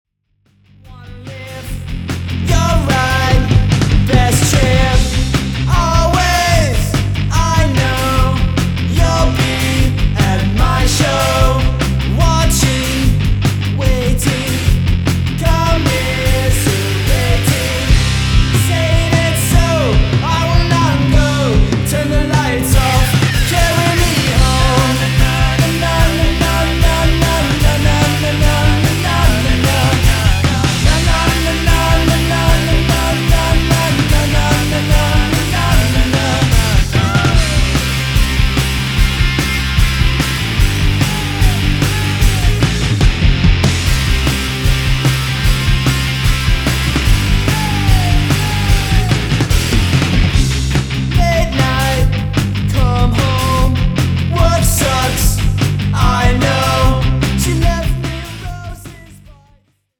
Guitar
Bass
Drums